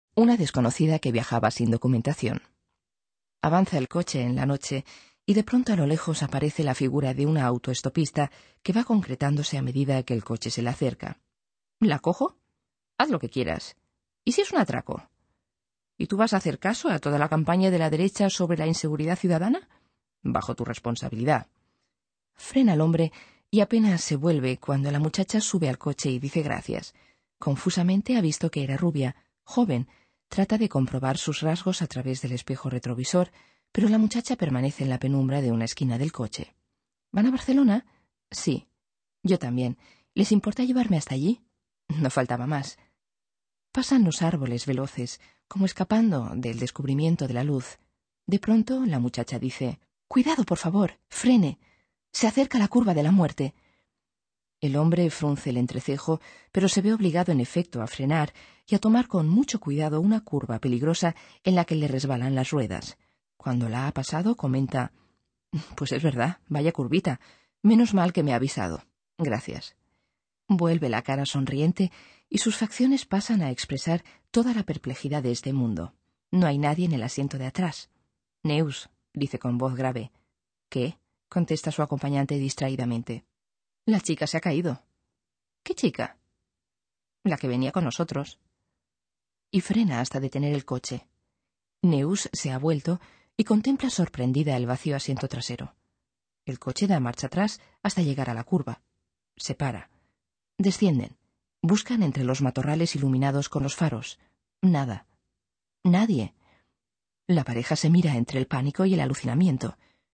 Interaktives Hörbuch Spanisch